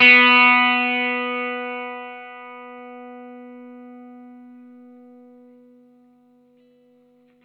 R12NOTE B +.wav